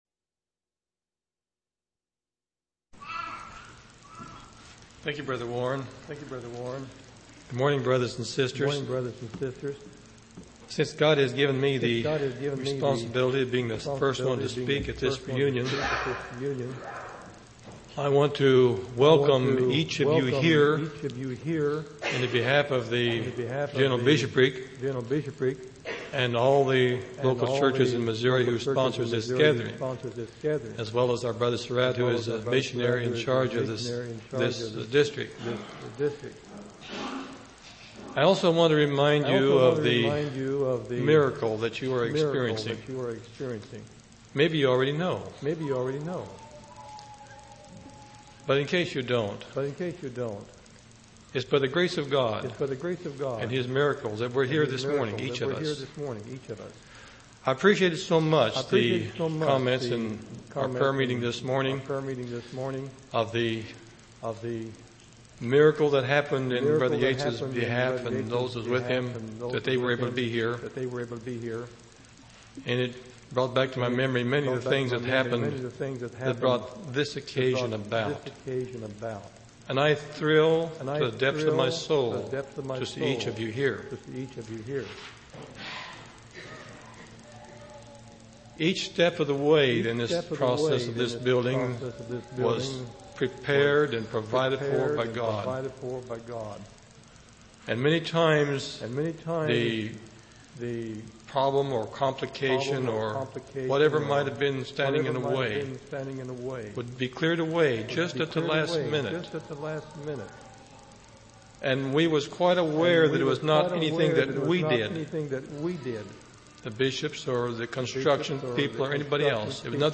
8/14/1992 Location: Missouri Reunion Event